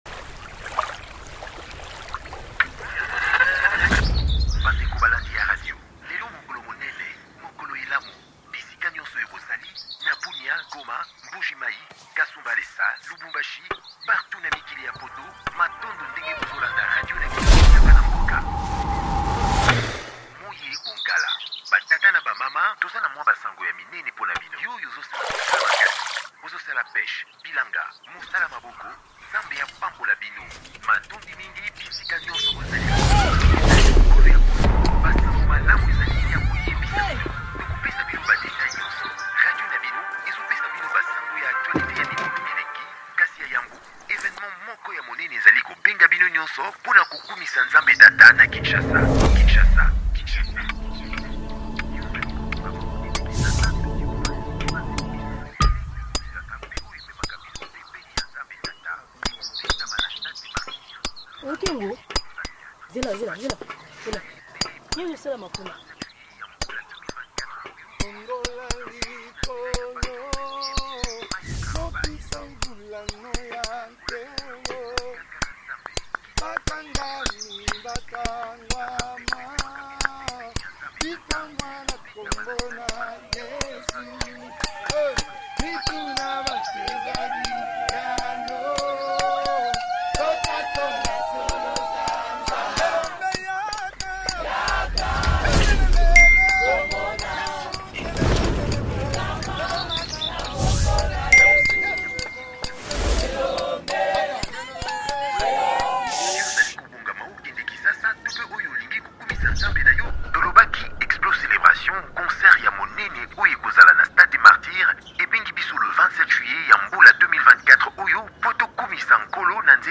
delivers a vibrant and uplifting worship track
reflects a high-energy worship experience
SOULFUL VOCALS, and a dynamic WORSHIP ARRANGEMENT